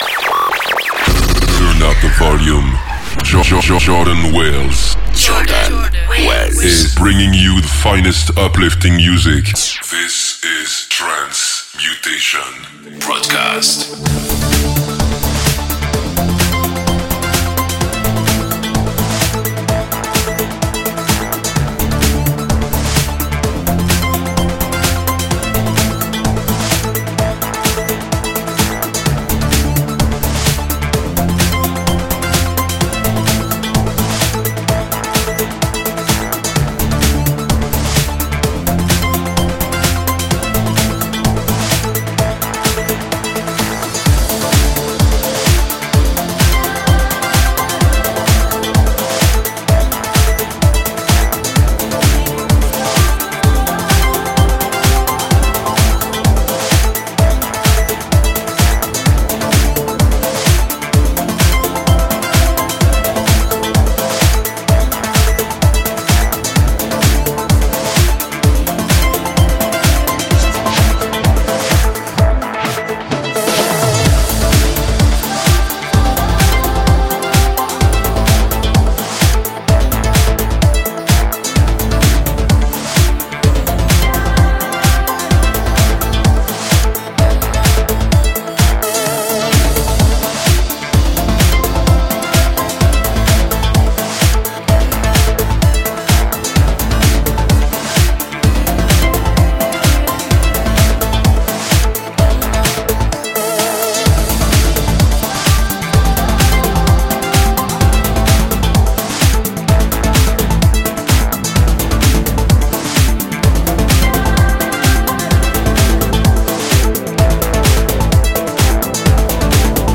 uplifting